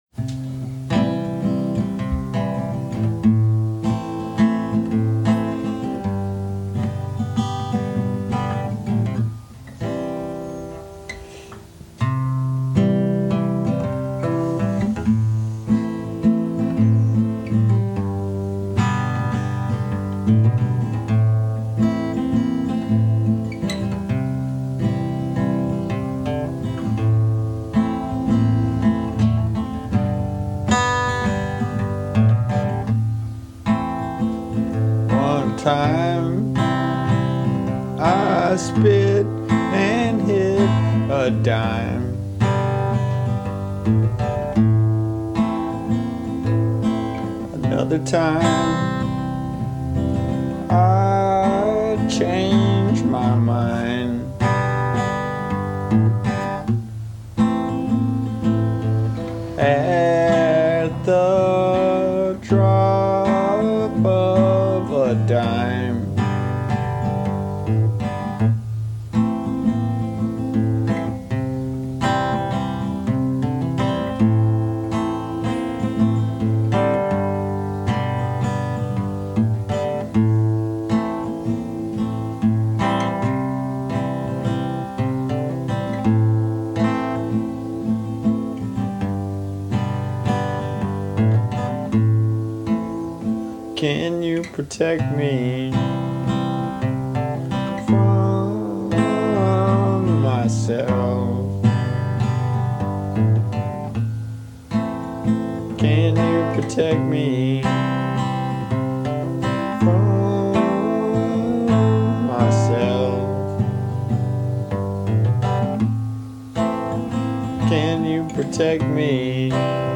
Hole up and find a good quiet record to listen to.